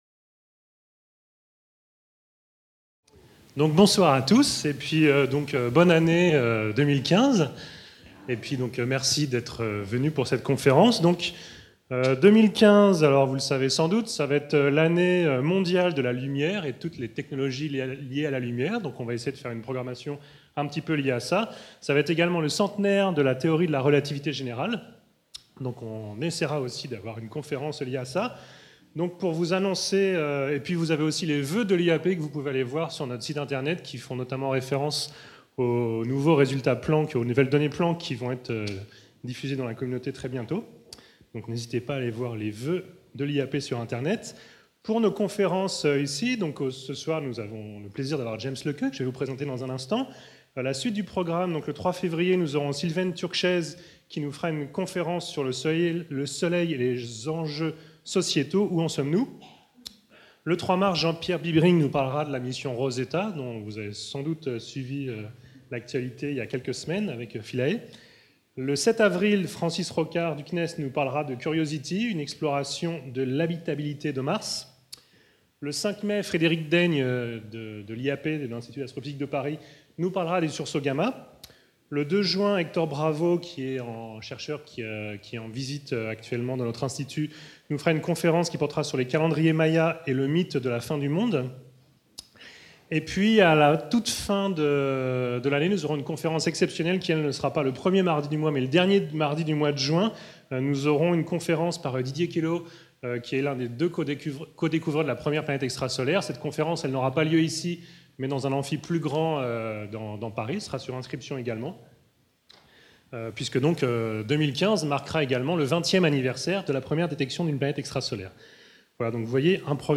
Conférence de l'Institut d'astrophysique de Paris (IAP)
le 6 janvier 2015 à l'IAP. L'Observatoire de Paris au 19e siècle a été dominé par deux fortes personnalités, aussi différentes que possible : François Arago de 1810 à 1853, et Urbain Le Verrier de 1854 à 1877.